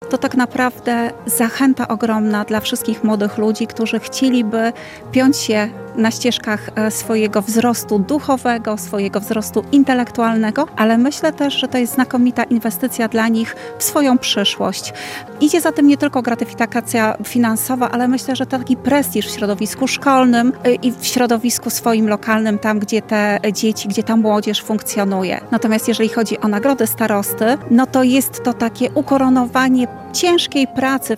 Jak podkreśla Anna Gawrych wicestarosta łomżyński – to wyjątkowe wyróżnienie dla młodych ludzi za ich pasję i wytrwałość oraz zachęta do dalszego rozwoju